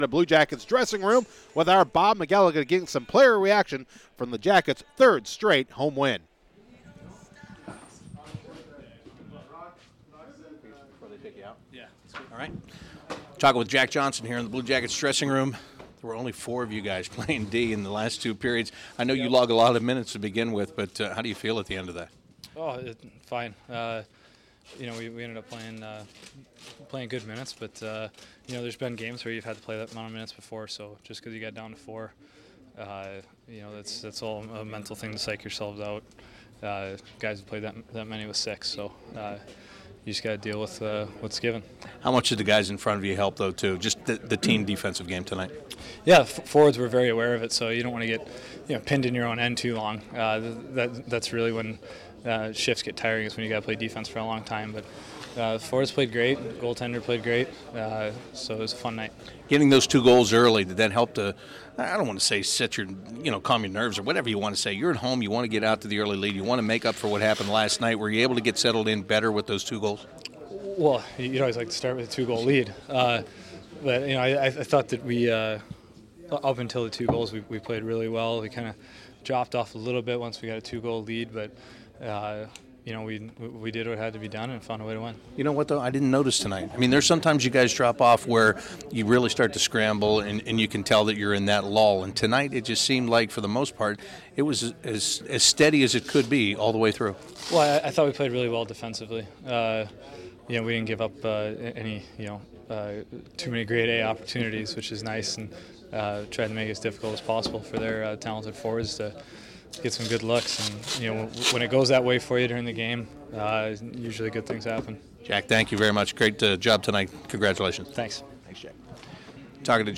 CBJ Interviews / Jack Johnson, Brandon Saad, Sergei Bobrovsky and Ryan Johansen
In the locker room as the Jackets celebrate their third consecutive home win and their first shut out! The Jackets beat the Nashville Predators 4-0 Friday Nov. 20 inside Nationwide Arena.